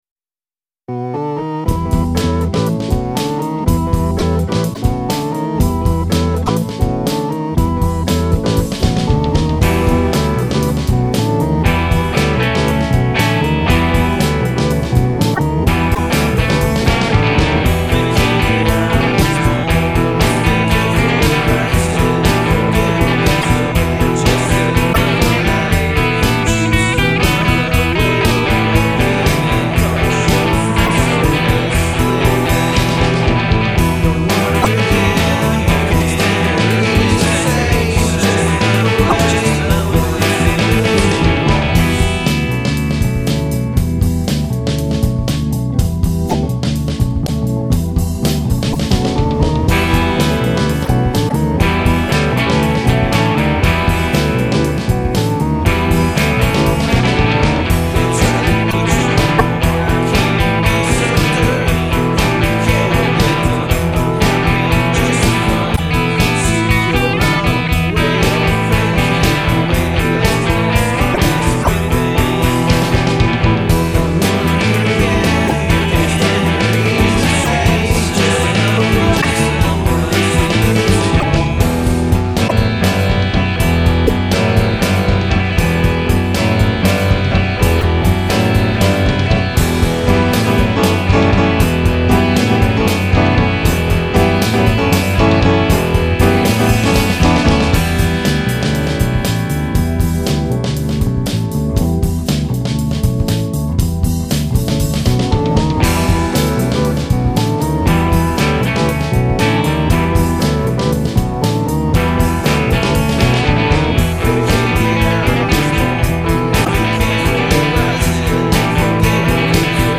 psychedelic consciousness.